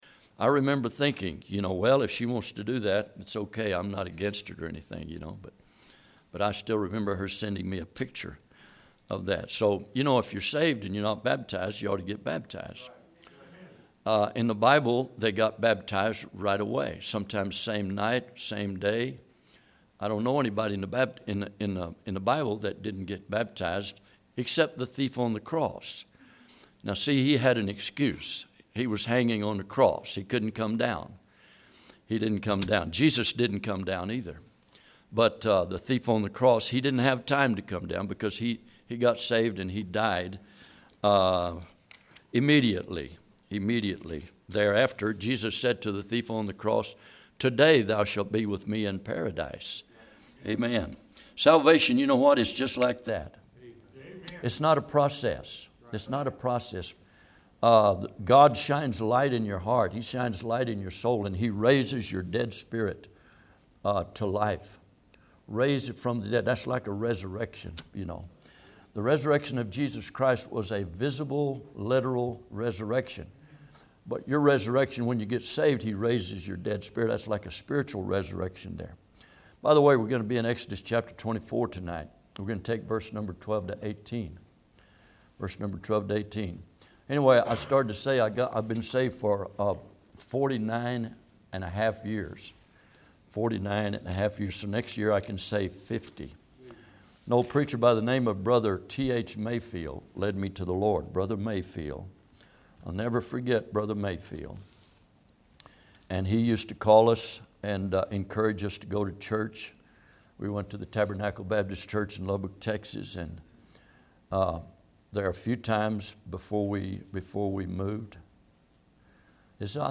Exodus 24:12-18 Service Type: Midweek Bible Text